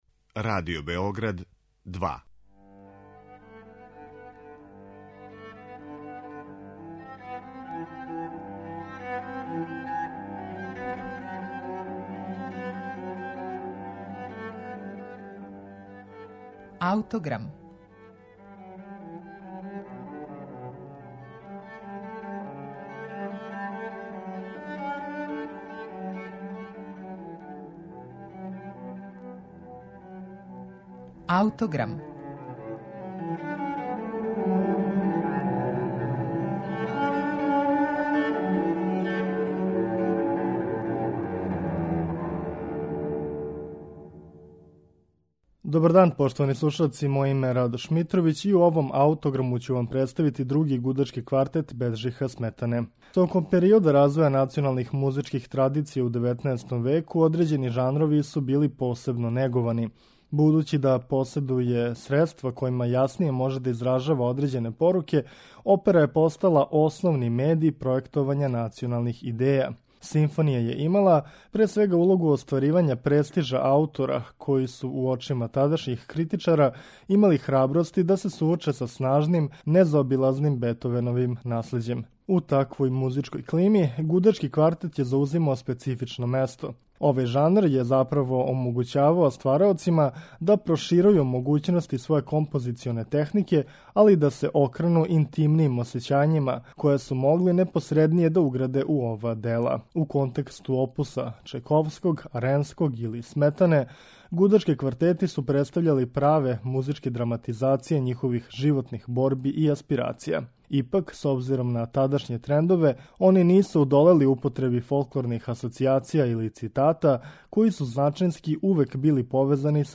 Крећући се карактерно између гнева, патње и меланхолије, овај квартет представља својеврсну интимну исповест чешког композитора, који је преминуо убрзо након завршетка овог дела. Други гудачки квартет Беджиха Сметане, слушаћемо у извођењу квартета Штамиц.